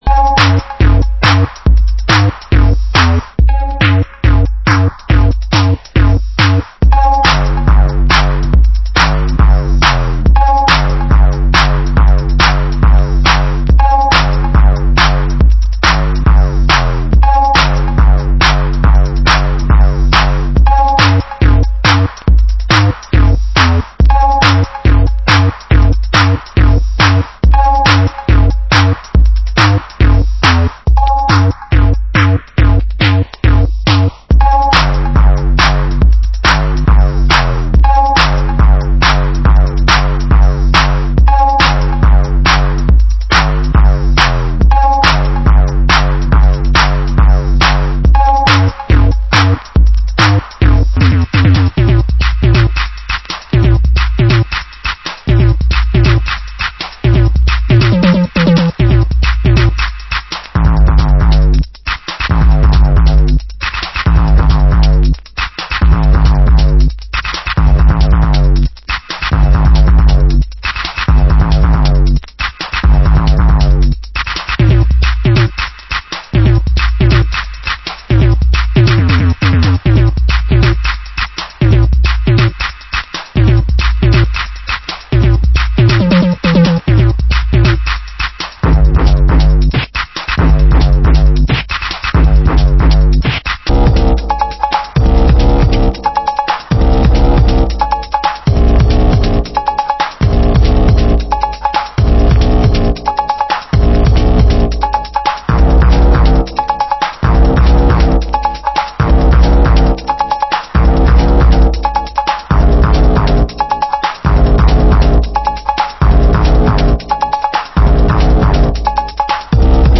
Genre: Grime